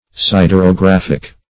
Siderographic \Sid`er*o*graph"ic\
siderographic.mp3